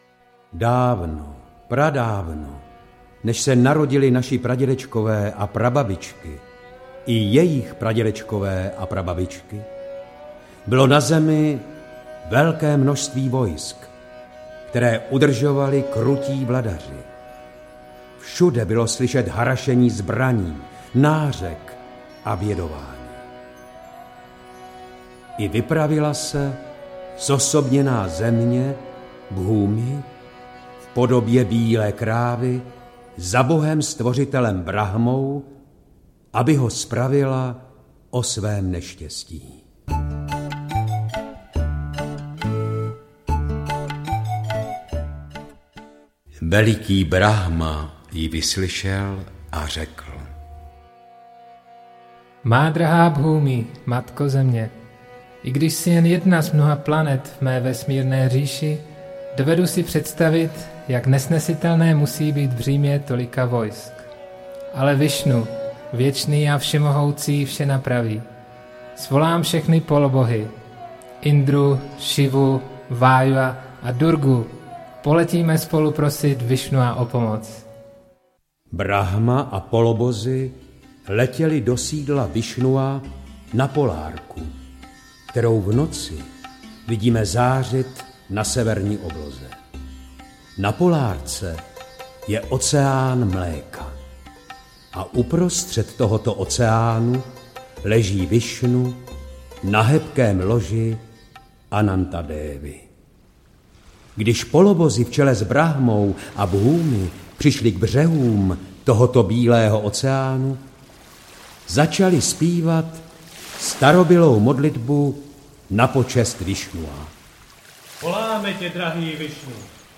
Krišna Avatár audiokniha
Ukázka z knihy